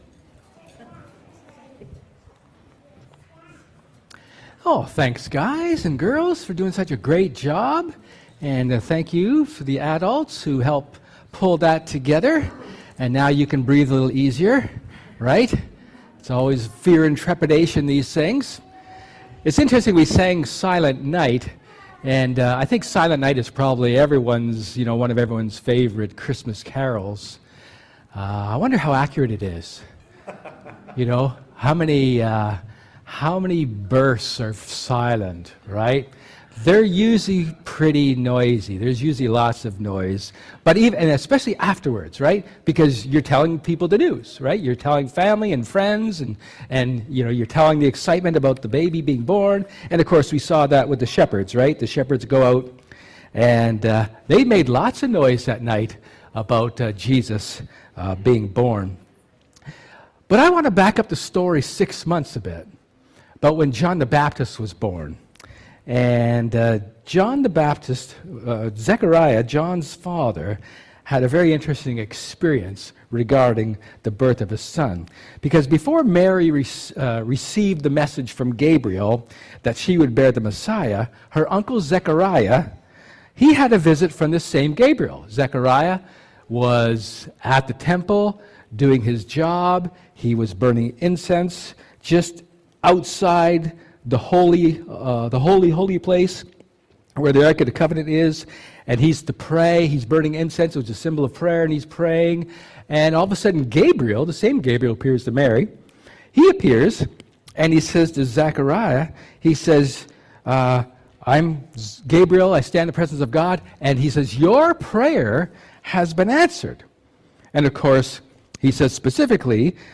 Luke 1:57-80 Service Type: Sermon A brief look at Zechariah's song of joy knowing that Christ was coming.